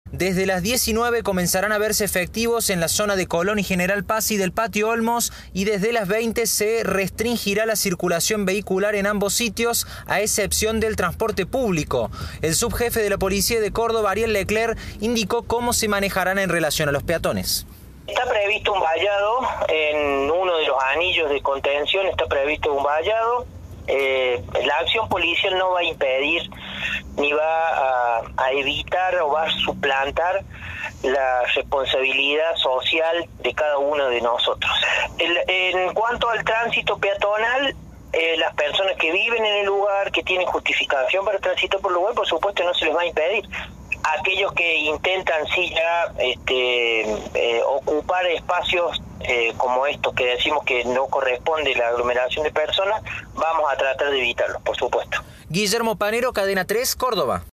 Tras el acto oficial del Gobierno de Córdoba por el Día de la Independencia, la Jefa de la Policía de Córdoba, Liliana Rita Zárate Belleti, indicó a Cadena 3 que habrá un fuerte operativo de seguridad para prevenir las aglomeraciones en los posibles festejos por la final de la Copa América.